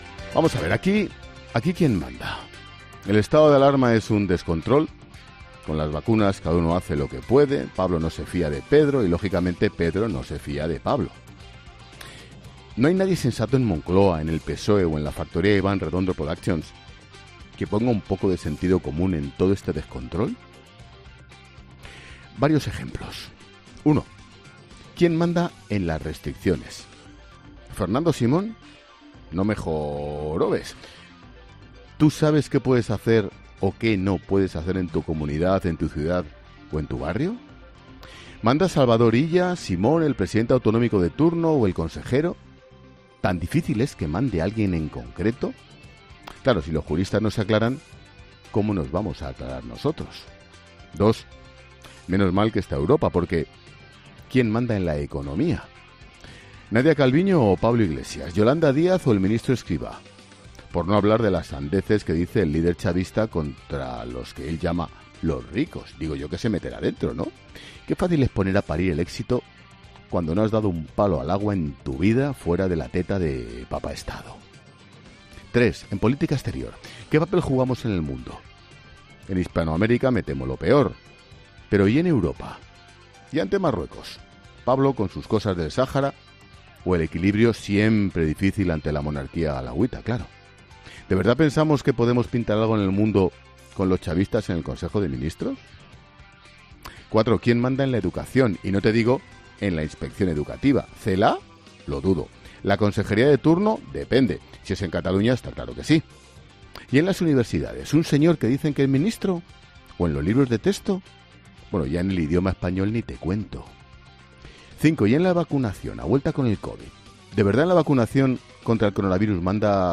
Monólogo de Expósito
El director de 'La Linterna', Ángel Expósito, aborda diversas cuestiones en relación al Gobierno y su papel dentro y fuera de nuestras fronteras